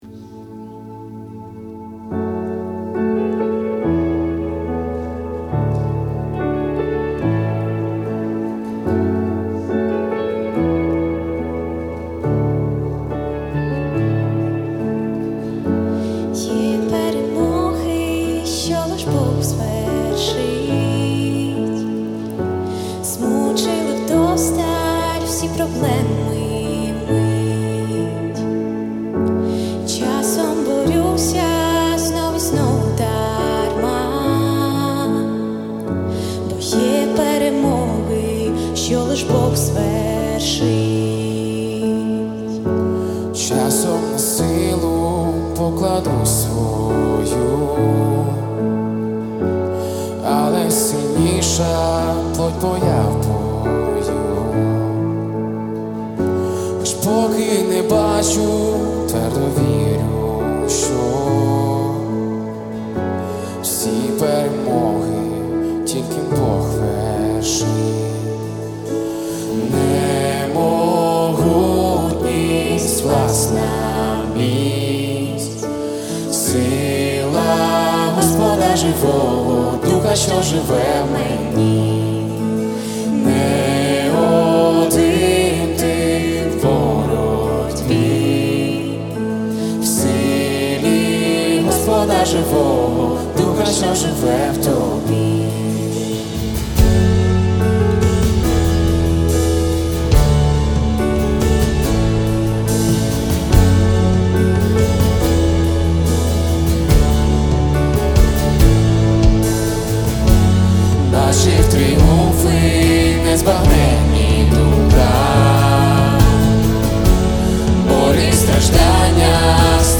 F#m  E  D  A
Worship Music
1228 просмотров 562 прослушивания 50 скачиваний BPM: 71